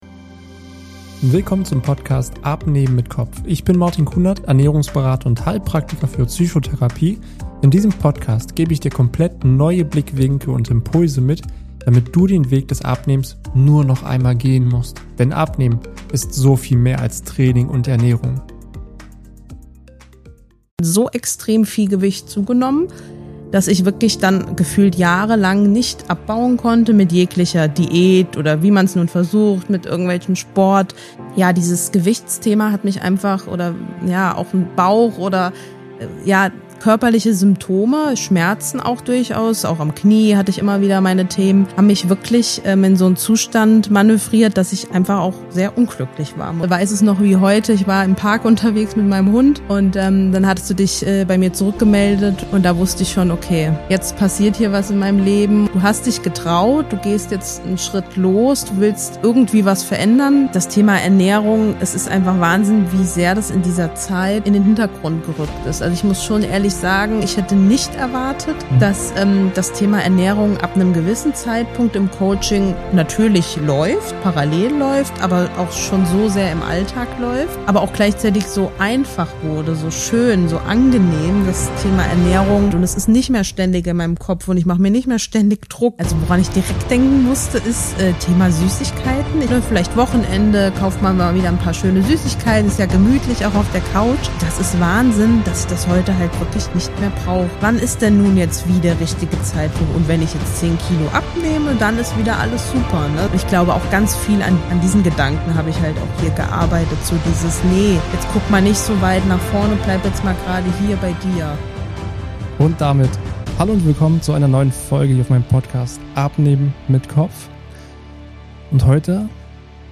Klienteninterview ~ Abnehmen mit Kopf